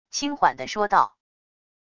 轻缓的说道wav音频